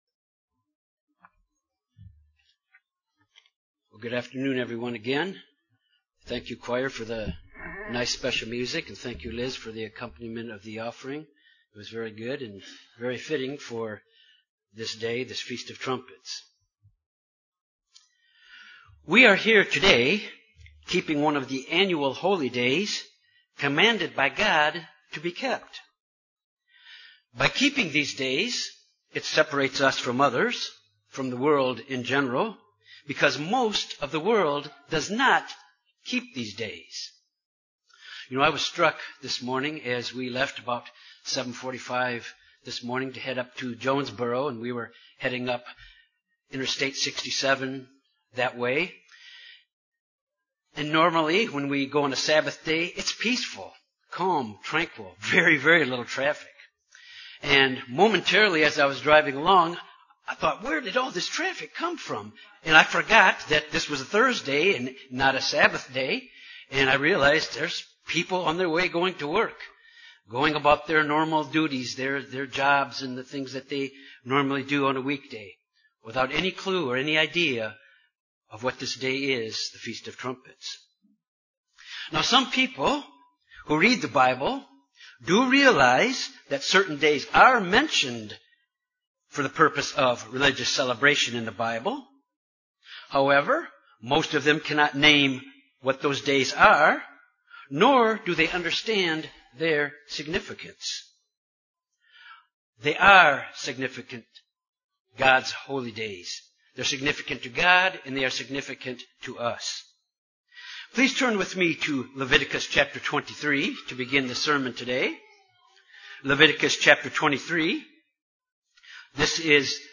This sermon examines the importance of the Feast of Trumpets as it relates to the firstfruits. It points to the day when Christ will return to this earth to rule with the saints.